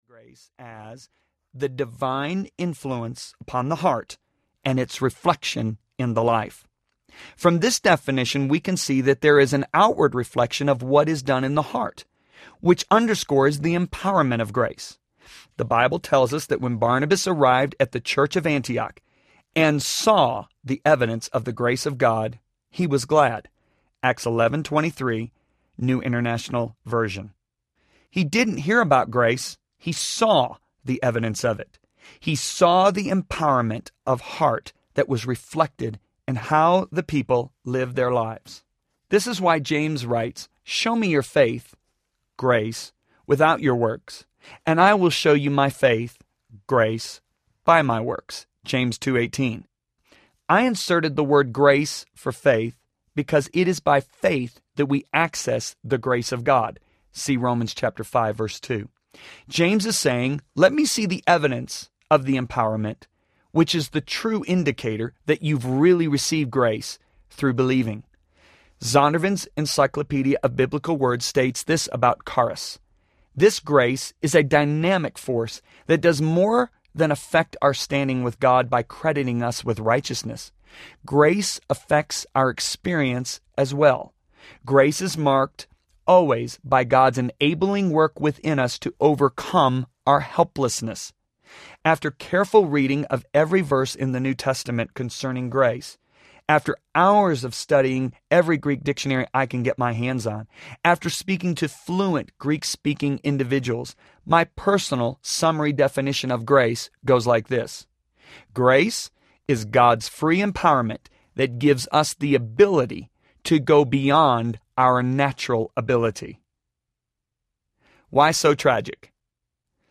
Relentless Audiobook
9.1 Hrs. – Unabridged